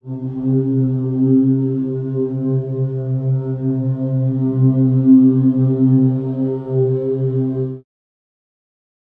描述：使用颗粒合成（我自己的实现）对尺八样本进行了大量处理
Tag: 粒状 尺八 合成